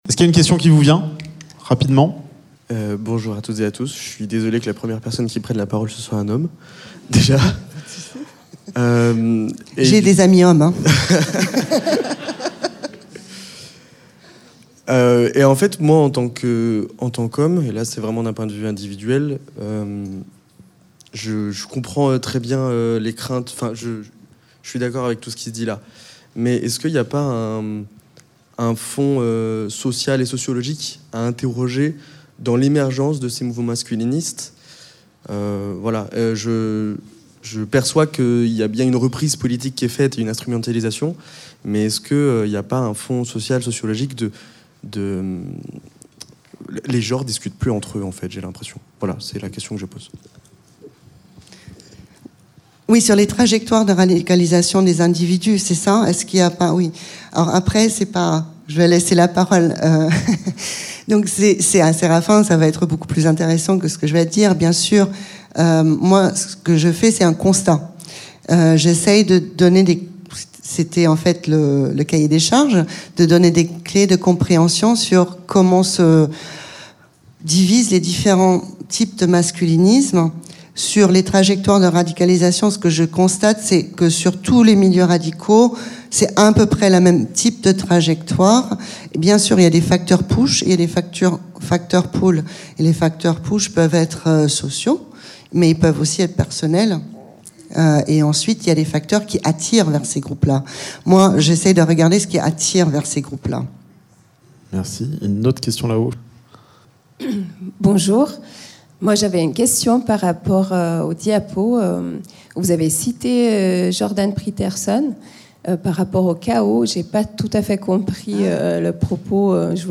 Rendez-vous réservé aux professionnels dans le cadre des Semaines de l’Égalité, la journée de formation s’est déroulée dans les locaux de l’Université Jean Monnet de Saint-Etienne le jeudi 06 Mars. Le CIDFF 42, SOS Racisme 42, Zoomacom et l’ANEF Loire organisaient une série de conférences et ateliers, en partenariat avec d’autres structures du territoire, pour décrypter le masculinisme.
Échanges avec le public